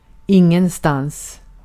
Ääntäminen
Ääntäminen US Tuntematon aksentti: IPA : /ˈnoʊ.wɛɹ/ IPA : /ˈnəʊ.wɛə/ Haettu sana löytyi näillä lähdekielillä: englanti Käännös Ääninäyte Adverbit 1. ingenstans Määritelmät Adverbit In no place .